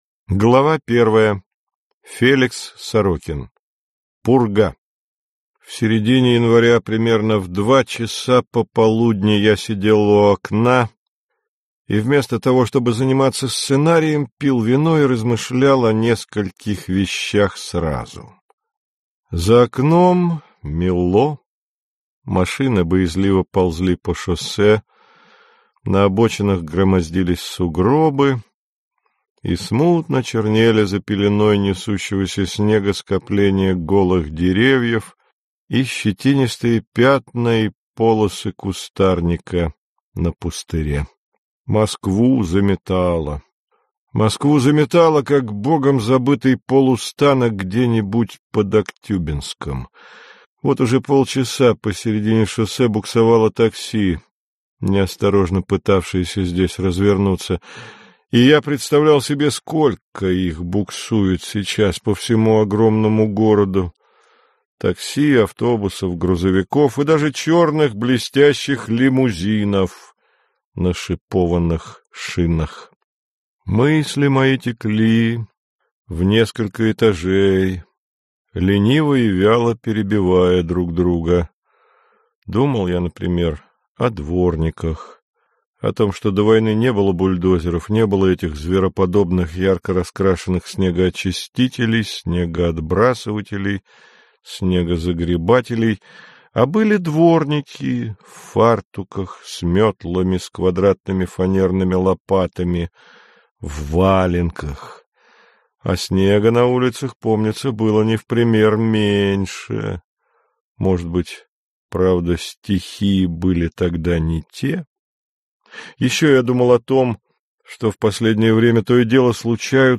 Аудиокнига Хромая судьба | Библиотека аудиокниг